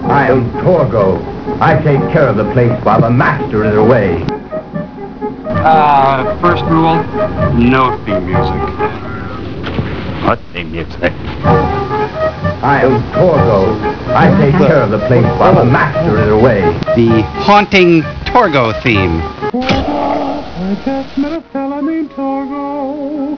Click on the picture of Torgo at the top of the page to hear my new, improved original mix of Torgo .WAVs!